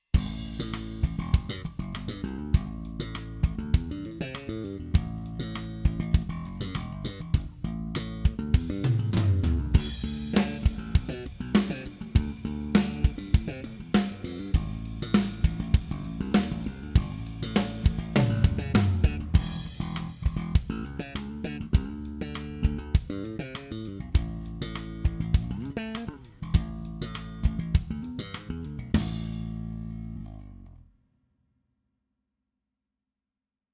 이 펑키 베이스 라인은 타악기 슬래핑, 리듬감 있는 고스트 노트, 글리산도 효과를 포함한다.